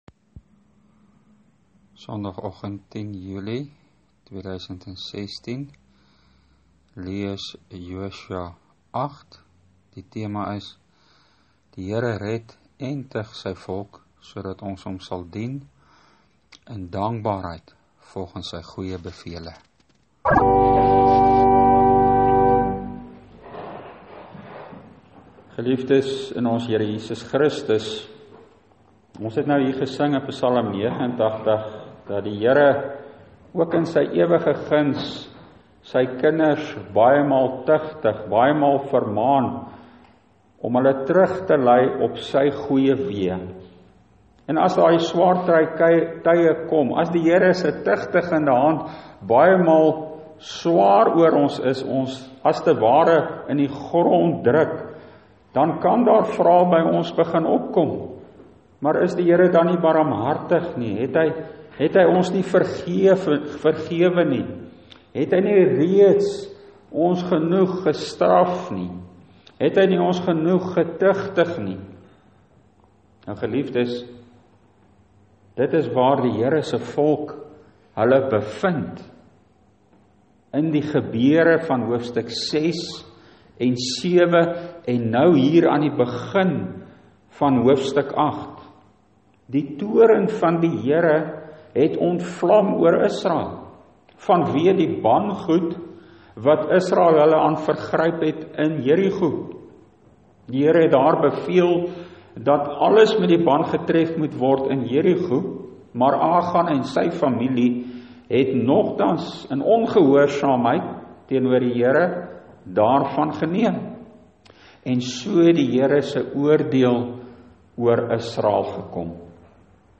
Preekopname (GK Carletonville, 2016-07-10):